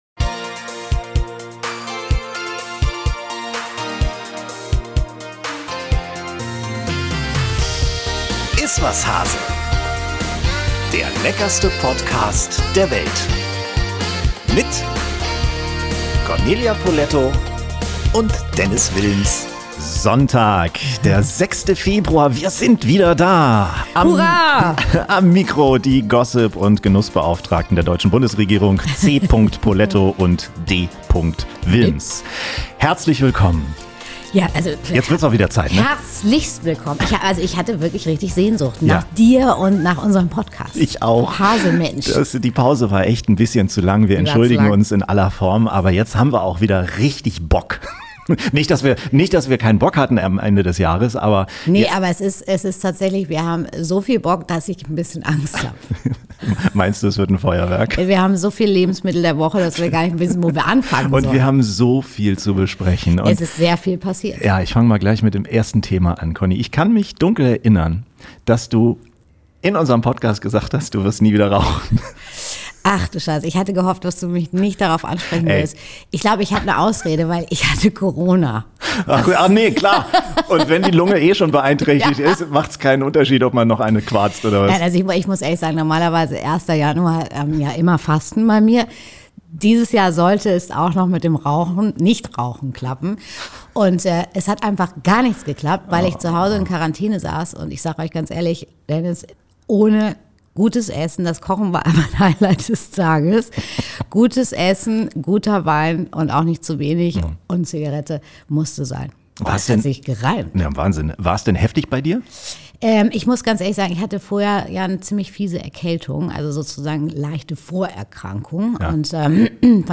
Gast: Ingo Nommsen